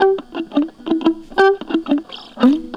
Track 02 - Guitar Lick 01.wav